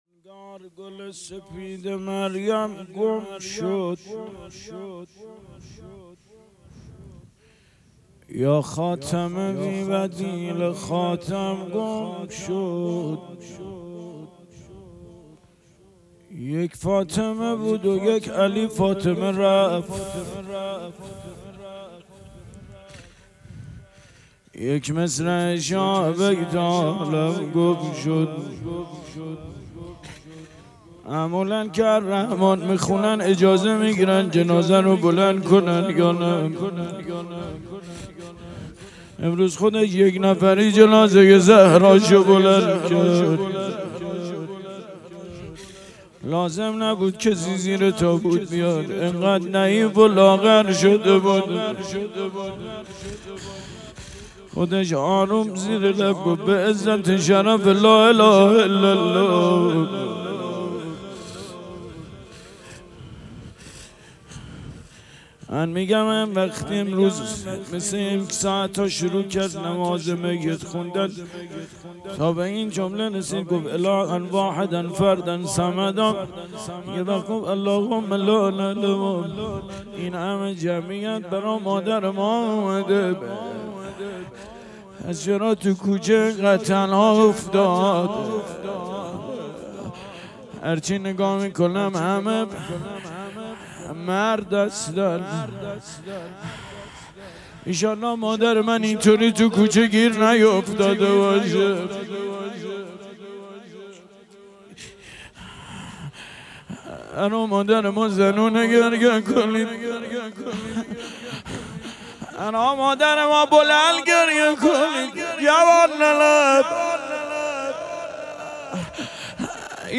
شام غریبان حضرت زهرا علیها سلام - روضه